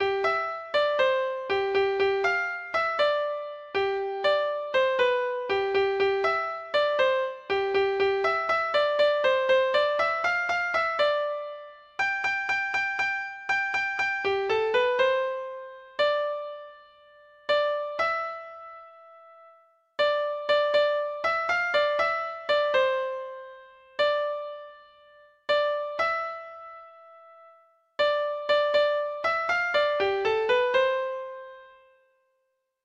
calypso